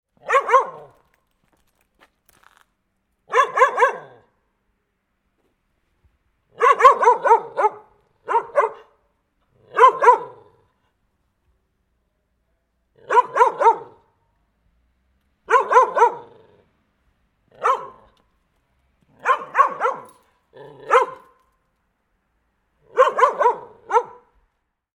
Close-Up Guard Dog Barking Sound Effect
Description: Close-up guard dog barking sound effect. Medium-sized guard dog barking loudly in yard.
Dog sounds.
Close-up-guard-dog-barking-sound-effect.mp3